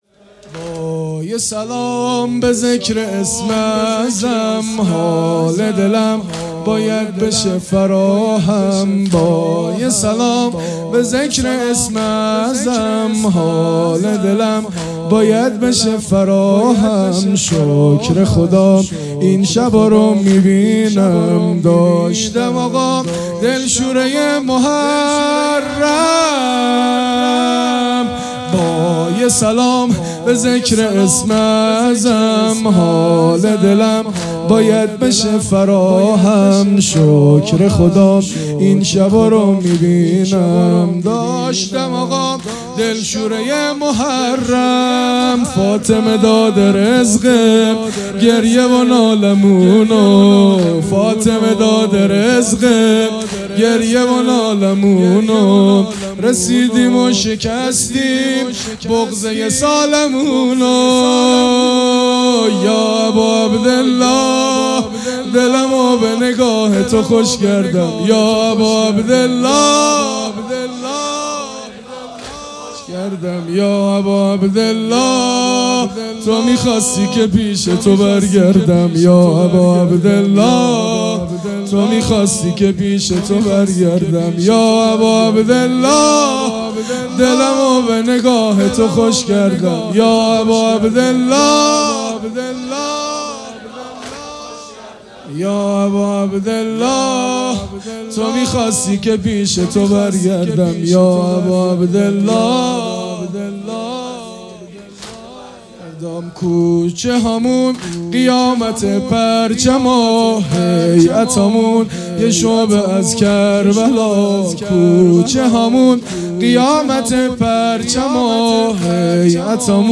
محرم الحرام ۱۴۴۵